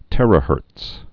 (tĕrə-hûrts)